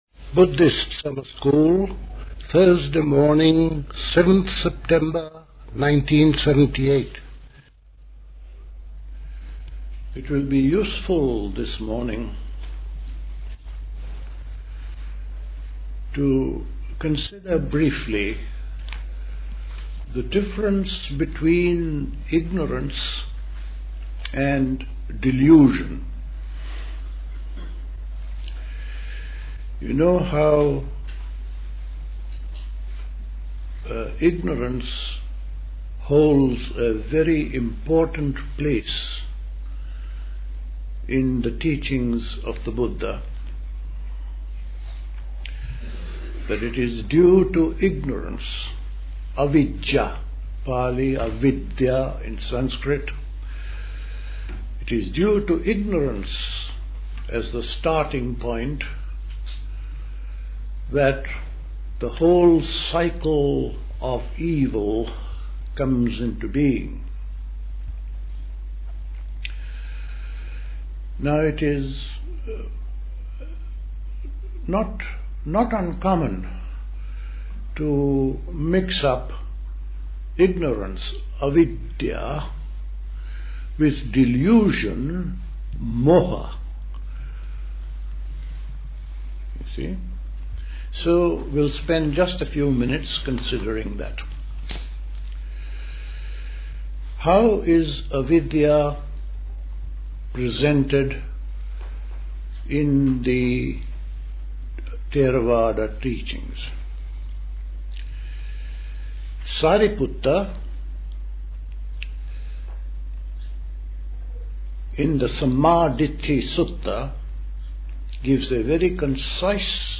A talk
at High Leigh Conference Centre, Hoddesdon, Hertfordshire on 7th September 1978